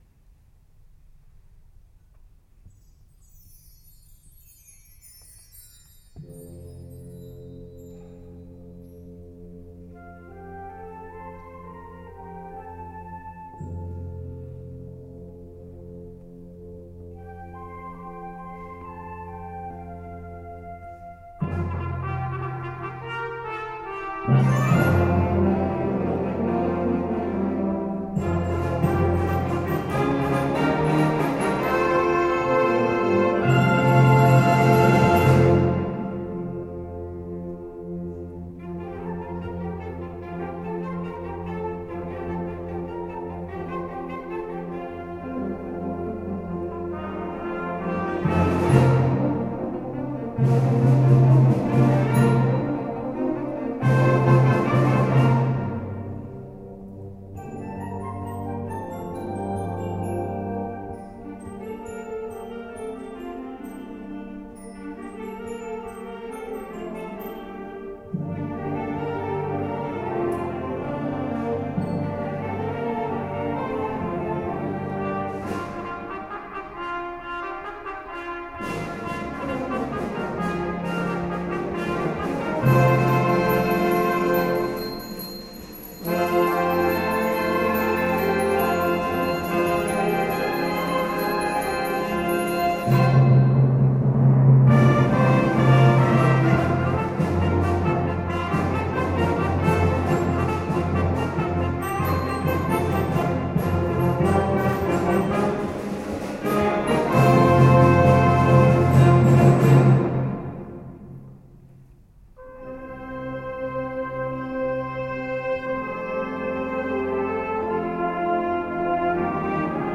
BANDA MUSICALE
Concerto di Natale 2011